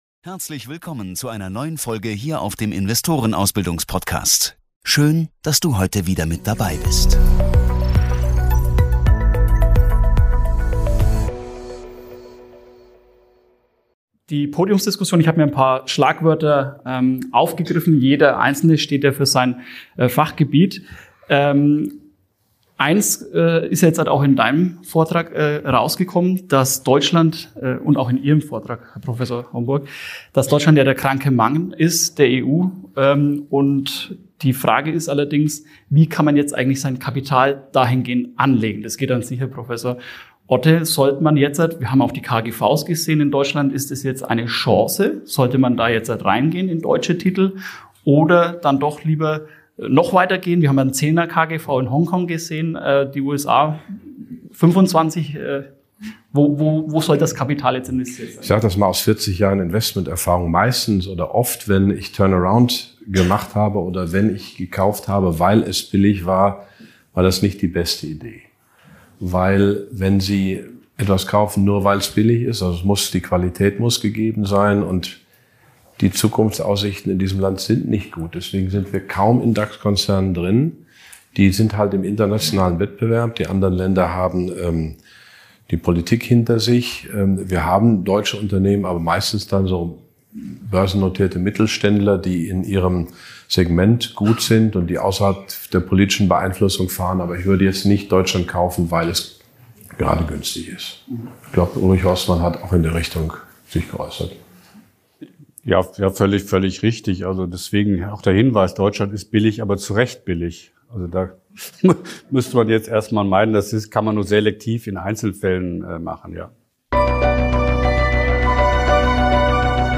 Sie diskutieren Deutschlands Wirtschaftslage, globale Investmentstrategien, Inflation, EZB-Politik und potenzielle Wirtschaftsreformen. Themen wie Staatsverschuldung, Modern Monetary Theory und die wirtschaftlichen Folgen der Corona-Pandemie werden ebenfalls behandelt. Die Experten bieten kontroverse Standpunkte und beantworten Fragen aus dem Publikum, was einen umfassenden Einblick in die aktuellen wirtschaftlichen und finanzpolitischen Debatten ermöglicht.